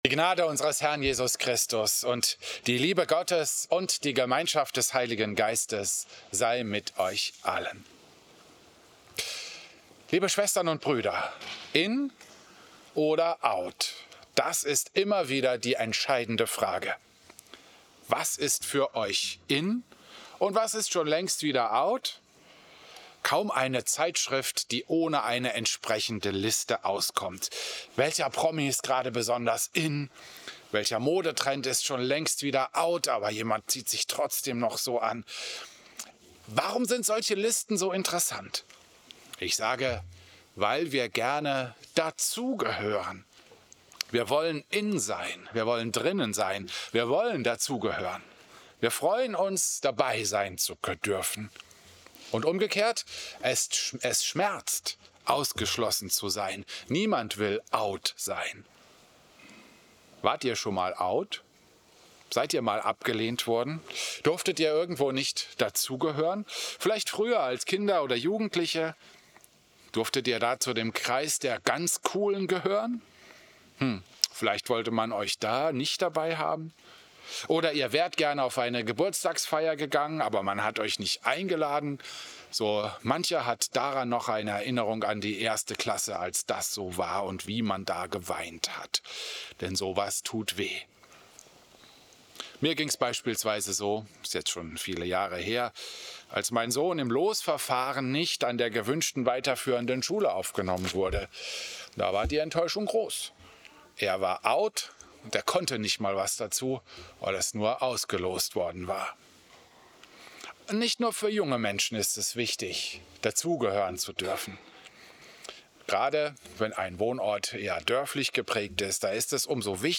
Christus-Pavillon Volkenroda, 14. September 2025